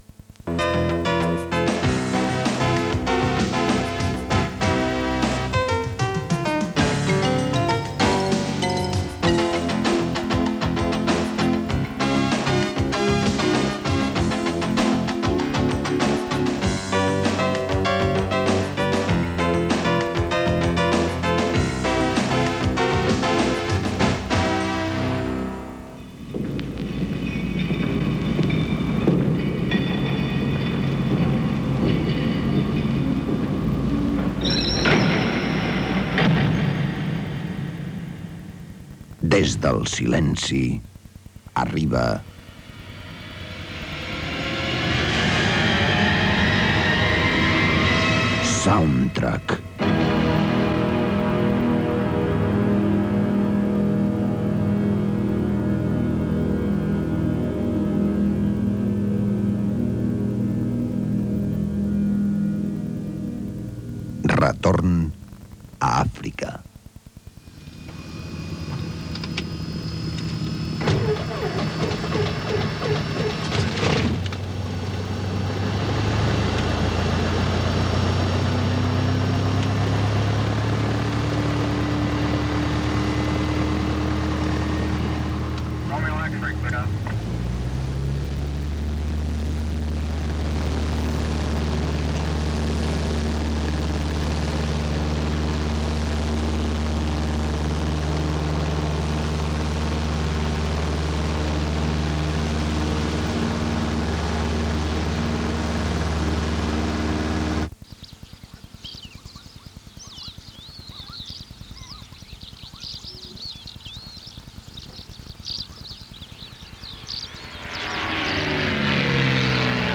Gènere radiofònic Ficció Data emissió 1990-07 Banda FM Localitat Barcelona Comarca Barcelonès Durada enregistrament 10:21 Idioma Català Notes Espai fet únicament amb música, efectes i ambients sonors.